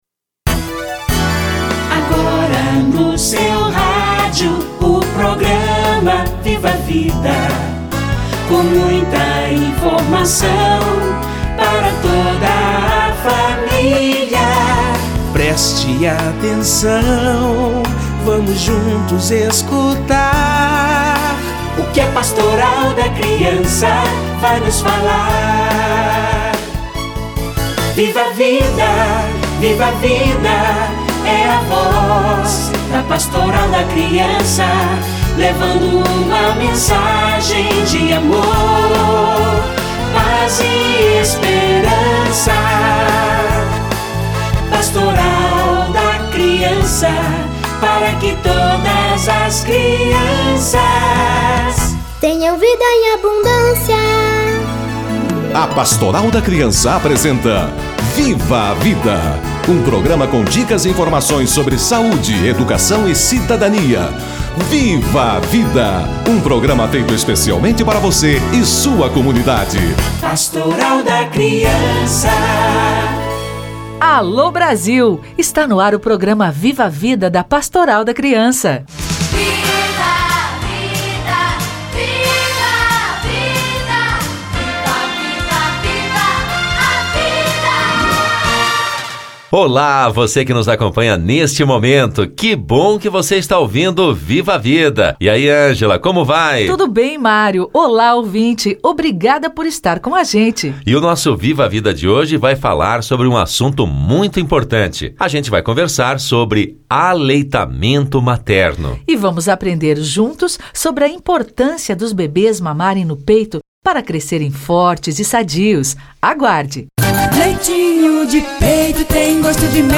Aleitamento materno - Entrevista